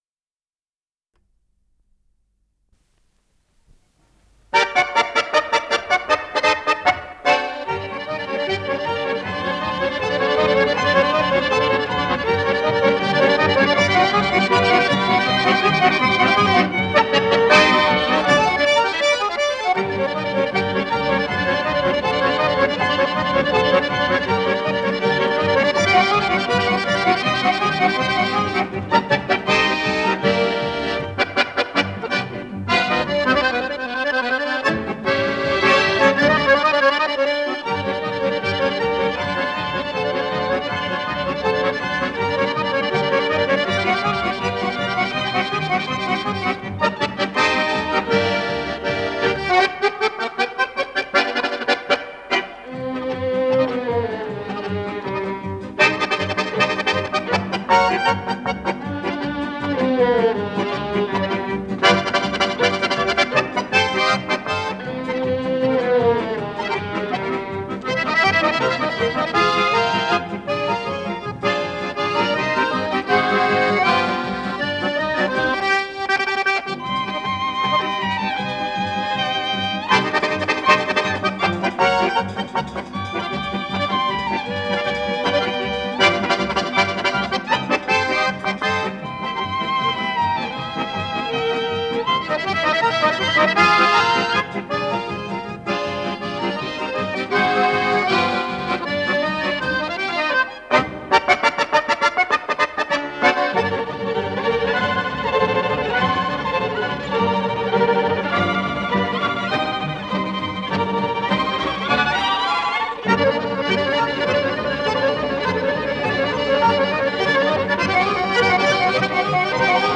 с профессиональной магнитной ленты
аккордеон
АккомпаниментДжаз-оркестр